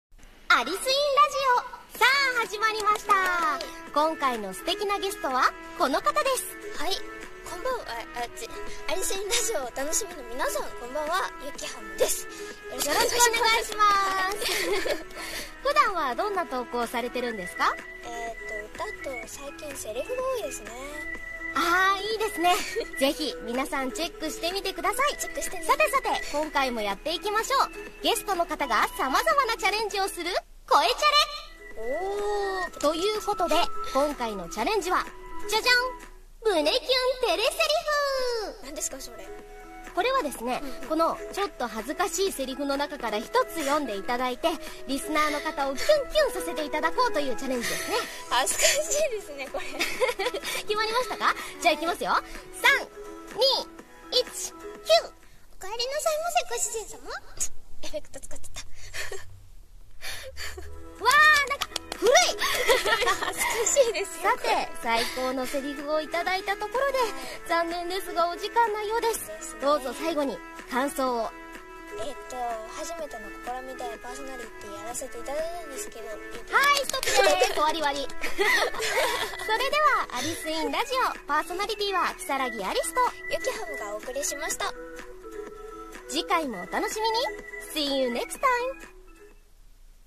【コラボラジオ】パーソナリティーやってみませんか？【掛け合い声劇】